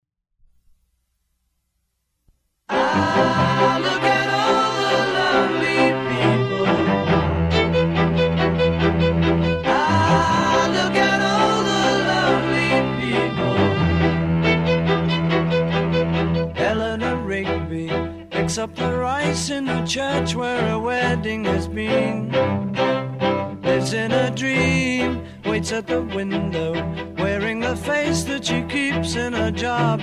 reel-to-reel 1 after - Two Squares
reel-to-reel-1-after.mp3